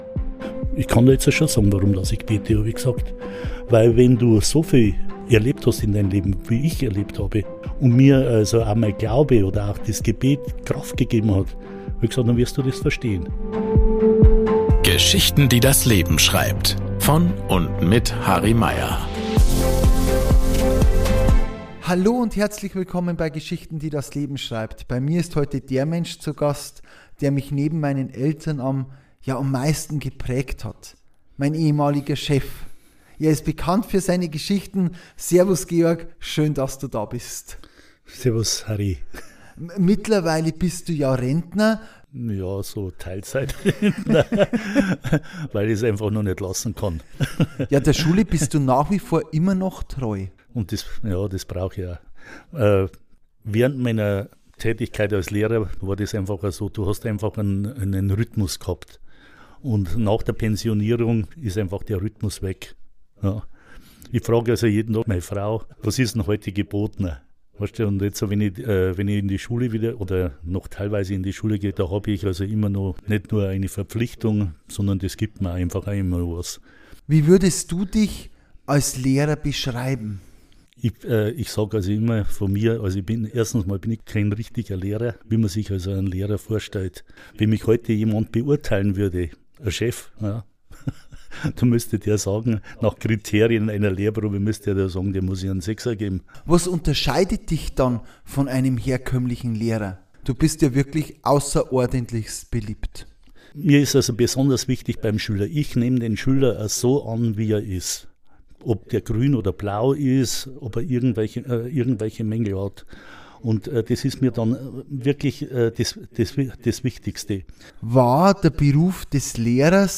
Die Gespräche sollen unterhalten, inspirieren und Mut machen – denn die schönsten Geschichten schreibt ja bekanntlich das Leben selbst.